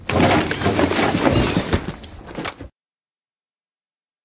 Ledge Climbing Sounds Redone